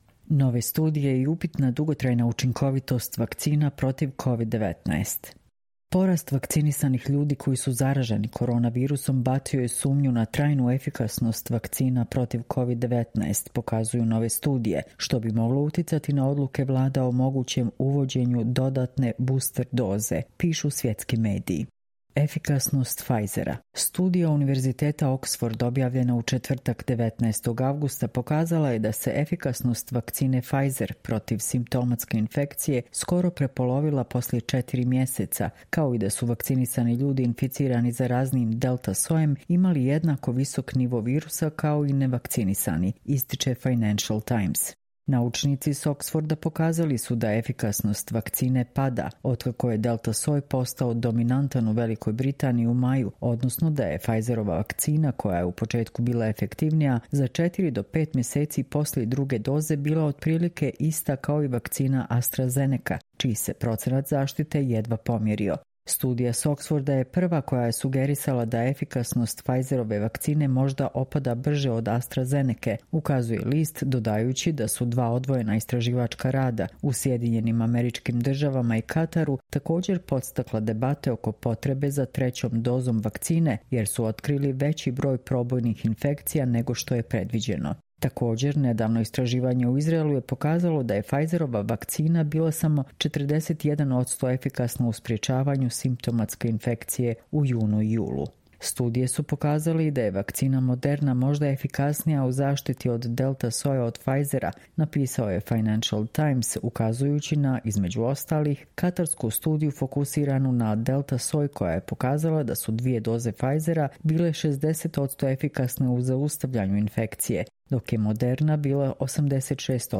Čitamo vam: Nove studije i upitna dugotrajna učinkovitost vakcina protiv COVID-19